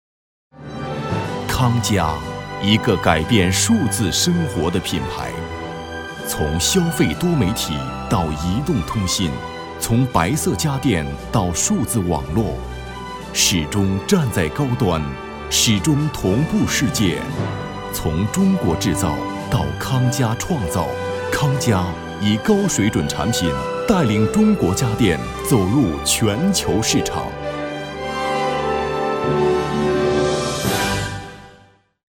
男35号-企业专题配音-大气-康佳集团